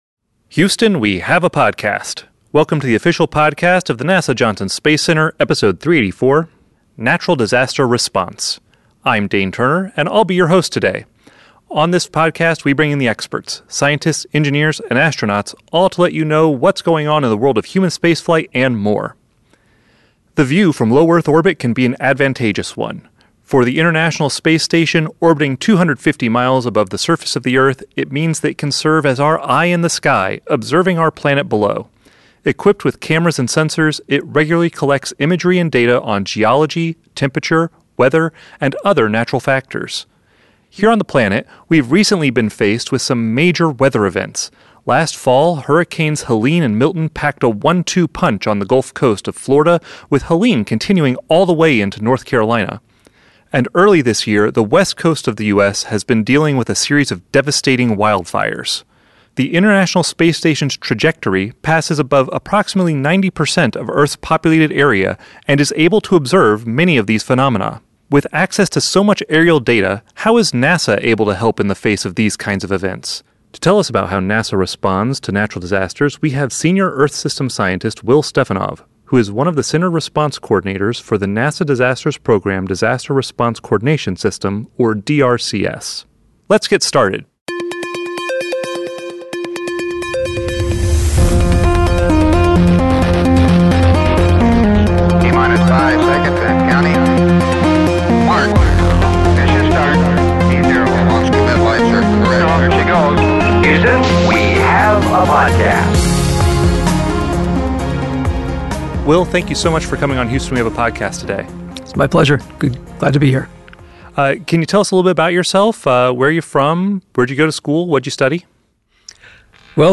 A NASA scientist discusses how imagery and data collected from the International Space Station can support natural disaster response teams on the ground.